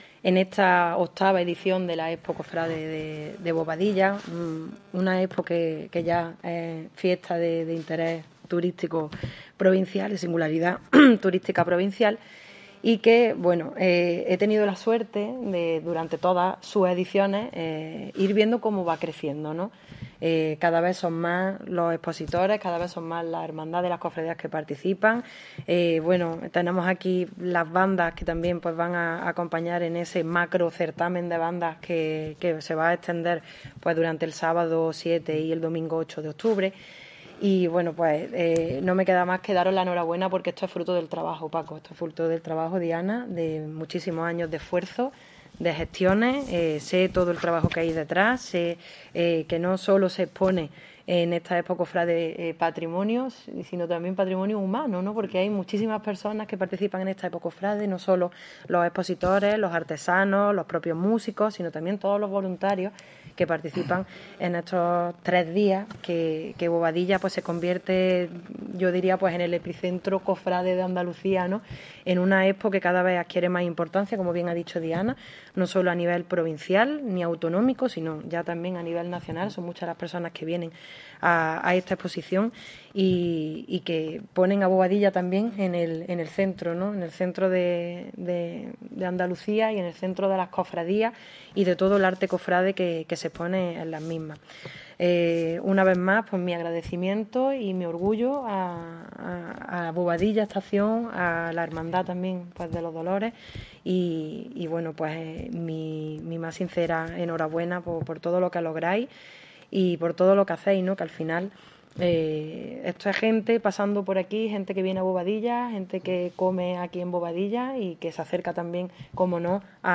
La teniente de alcalde de Tradiciones, Elena Melero, y el concejal delegado de Cultura y Patrimonio Histórico, José Medina Galeote, han asistido en el mediodía de hoy miércoles a la presentación oficial de la VIII Expo de Arte Cofrade que la entidad local autónoma de Bobadilla Estación acogerá del 6 al 8 de octubre, convirtiéndose así en epicentro del mundo cofrade durante ese fin de semana.
Cortes de voz